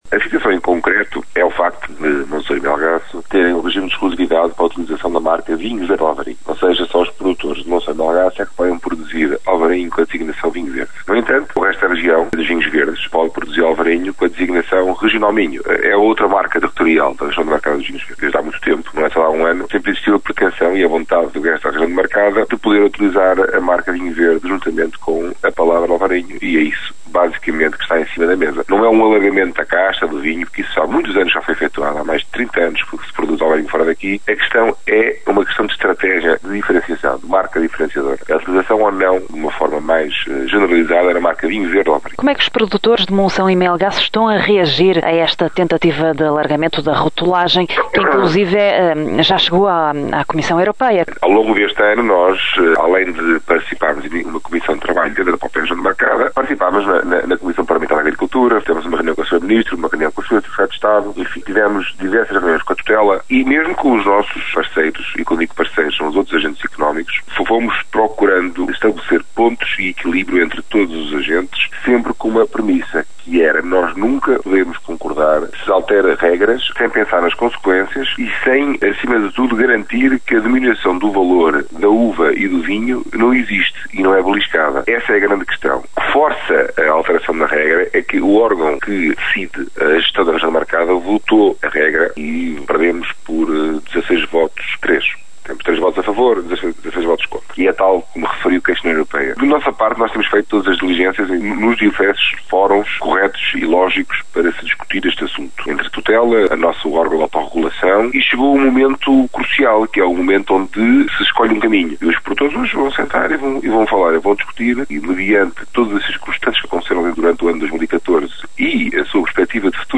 Em declarações à Rádio Caminha